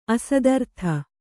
♪ asadartha